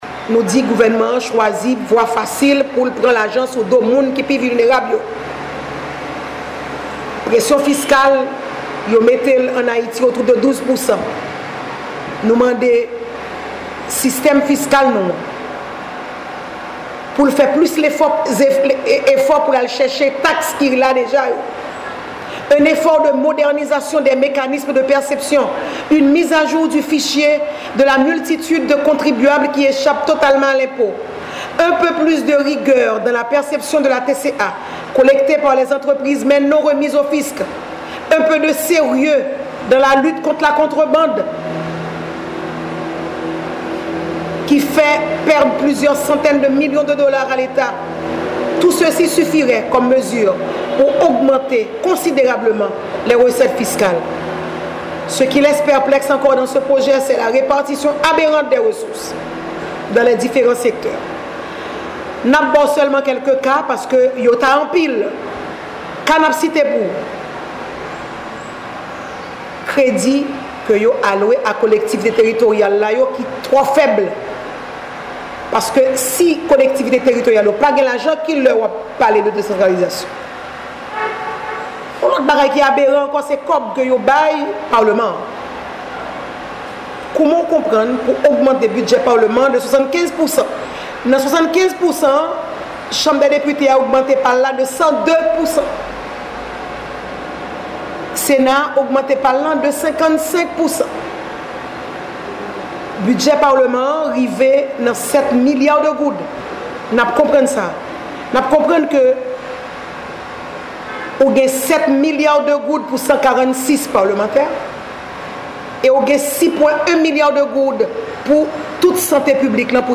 AUDIO – Edmonde Supplice Bauzile, présidente de la Fusion des sociaux-démocrates, dénonce les taxes forfaitaires imposées à certaines couches de la population dans le projet de loi de finances 2017 – 2018, ce afin d’augmenter les recettes de l’Etat.